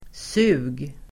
Uttal: [su:g]